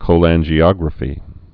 (kō-lănjē-ŏgrə-fē)